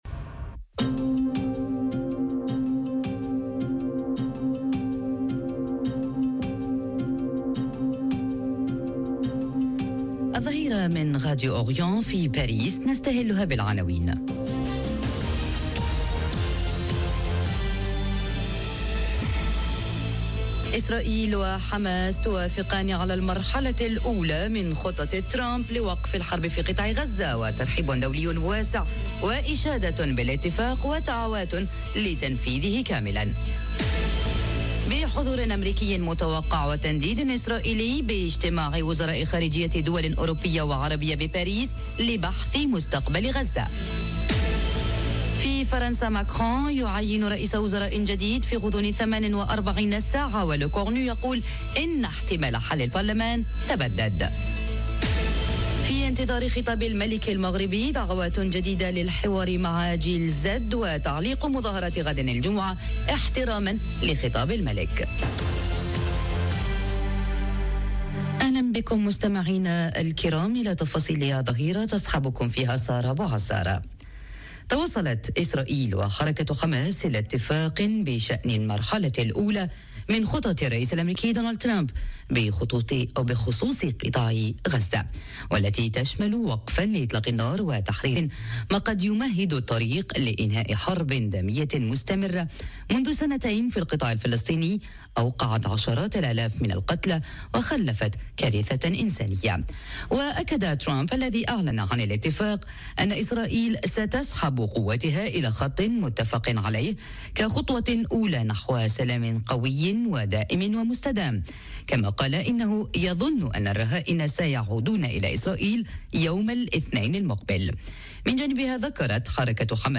نشرة أخبار الظهيرة: إسرائيل وحماس توافقان على المرحلة الأولى من خطة ترامب لوقف الحرب في قطاع غزة وترحب دولي واسع واشادة بالاتفاق ودعوات لتنفيذه كاملا - Radio ORIENT، إذاعة الشرق من باريس